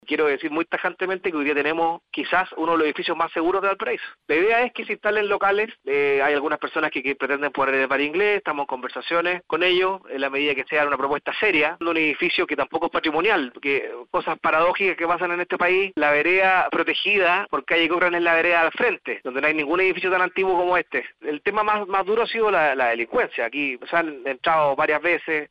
En conversación con Radio Bío Bío